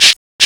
PERC LOOP5-R.wav